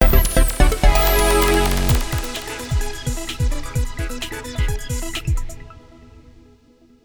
Sound effect for Club Nintendo Picross and Club Nintendo Picross+
CNP_-_SE_Completed_Puzzle.wav.mp3